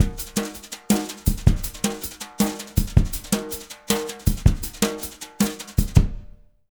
160JUNGLE5-R.wav